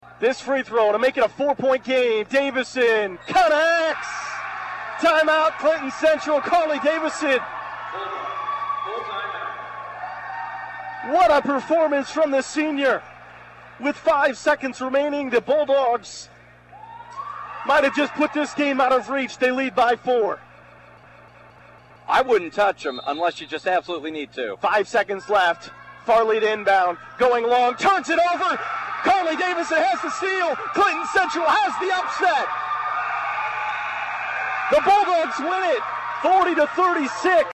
Clinton-Central-vs-Rossville-clip.mp3